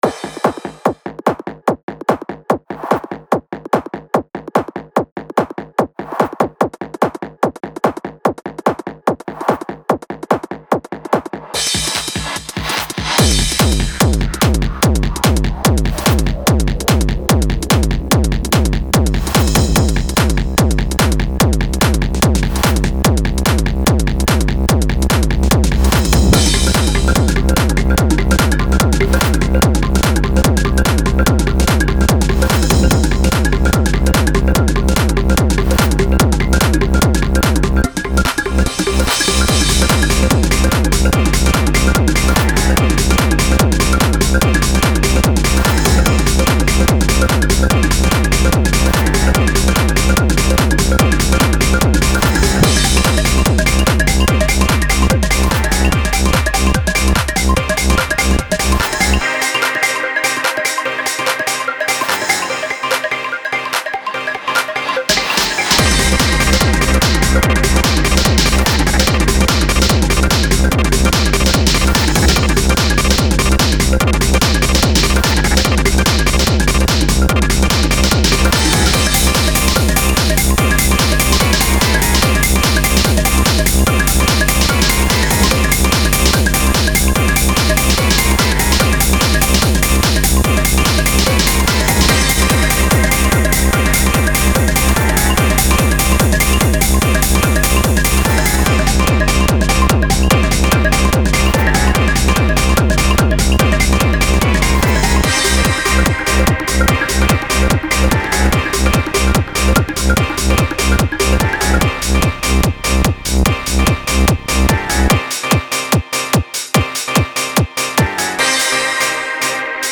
Featuring intense jetlag, and a lot of percussion.
There is also a buzzing bassline to tie it altogether, although it's not following any particular chord progression, as this week it's mainly just a one chord camp (Kinda). Long story short, it has the makings of a techno track right down to the structure and beat, but just in a slightly less conventional way.